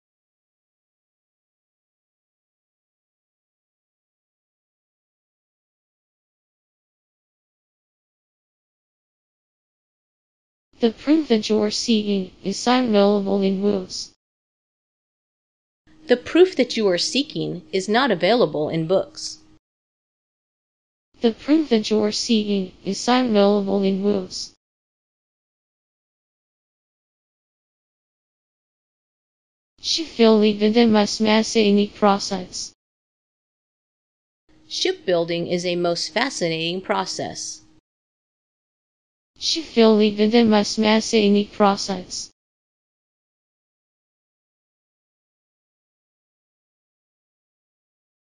研究者们让以英语为母语的人听了听合成的语音，结果表明，至少有70%的虚拟语言是可以被理解的。
speech-synthesis-from-neural-decoding-of-spoken-sentences-720h.mp3